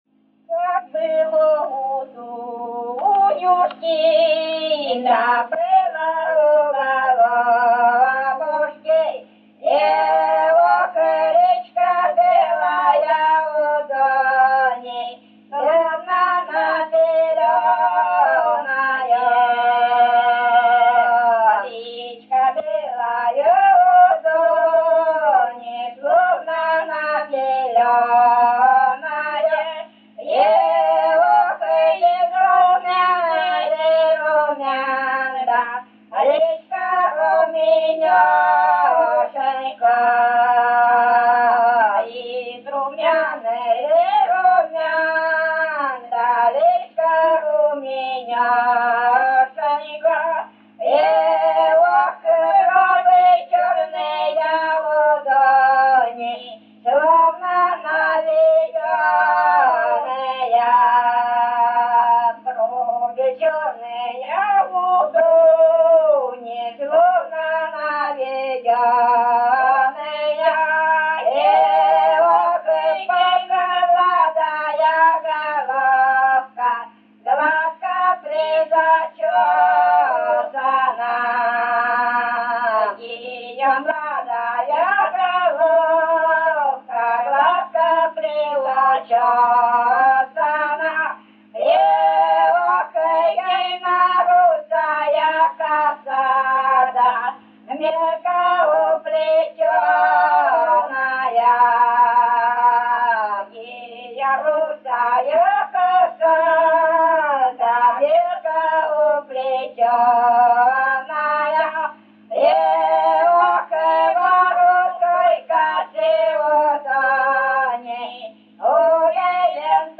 Место записи: с. Шуньга, Медвежьегорский район, Республика Карелия.